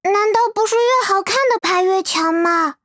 vo_card_klee_endOfGame_fail_01.wav